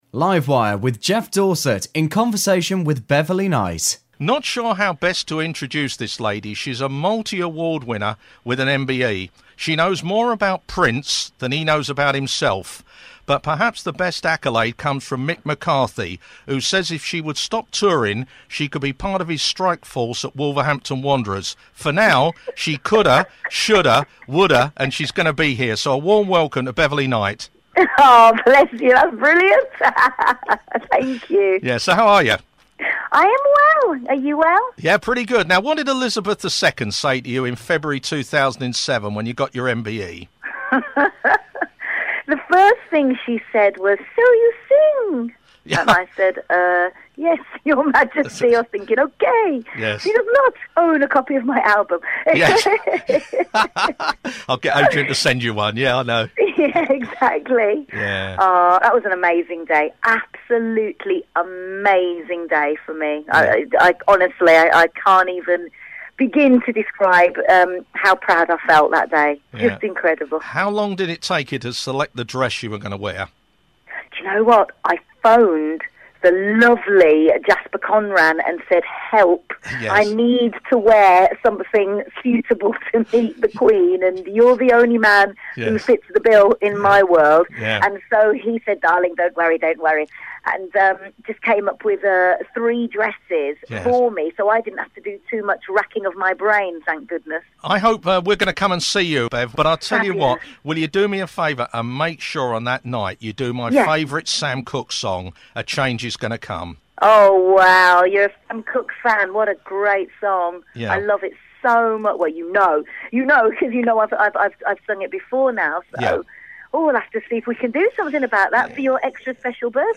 Live Wire Talks To Beverley Knight